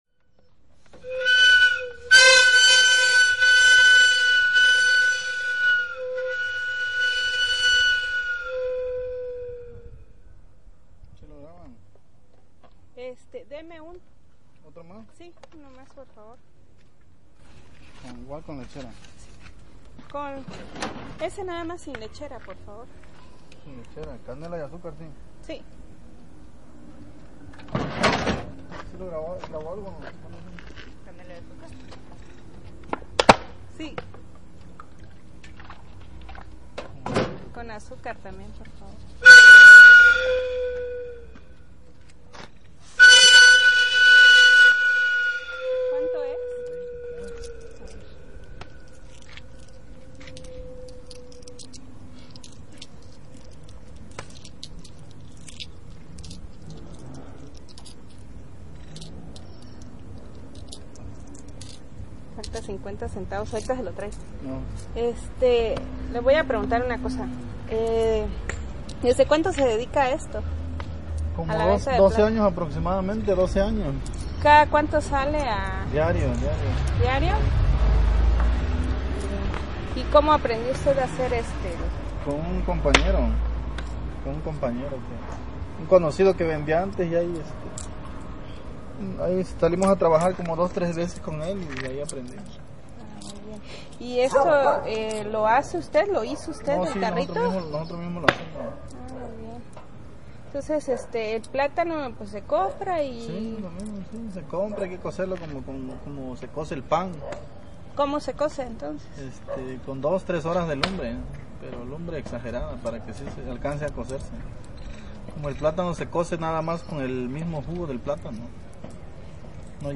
Platanero
El plantanero no necesita anunciar a viva voz lo que vende, el pequeño carro, hecho de pedazos de fierro que empuja por las calles, emite un sonido muy singular, el cual nos invita a salir a la calle y comprarnos el postre del día.
Lugar: Tuxtla Gutierrez, Chiapas; Mexico Fecha: 11 de diciembre de 2007 Hora: 18:25 hrs. Equipo: Minidisc NetMD MD-N707, micrófono de construcción casera (más info)